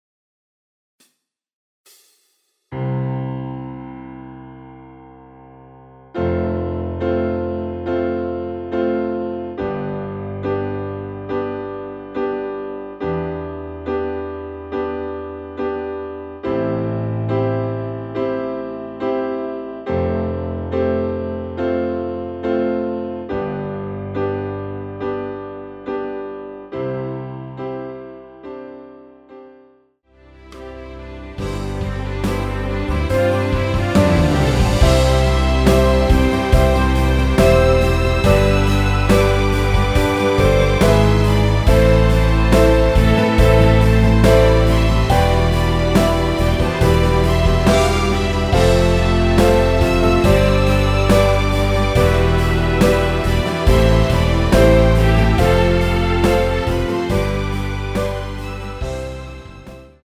음정은 반음정씩 변하게 되며 노래방도 마찬가지로 반음정씩 변하게 됩니다.
앞부분30초, 뒷부분30초씩 편집해서 올려 드리고 있습니다.
중간에 음이 끈어지고 다시 나오는 이유는